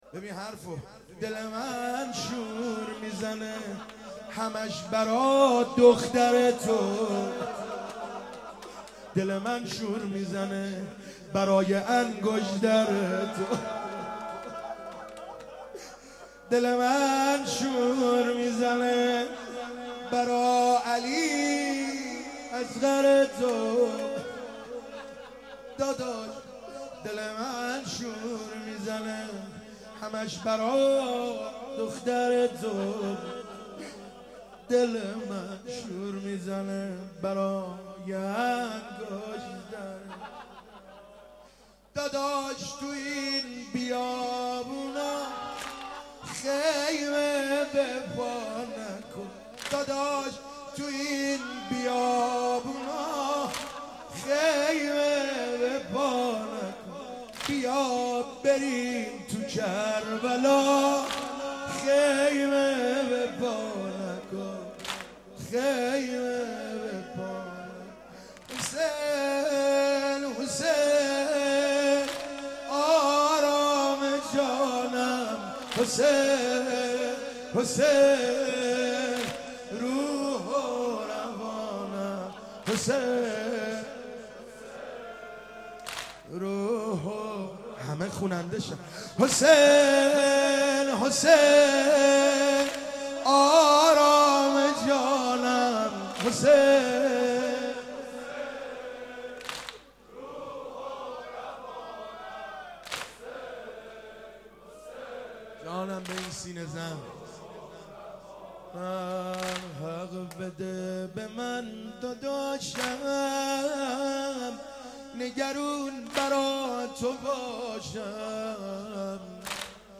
مداح
مناسبت : شب دوم محرم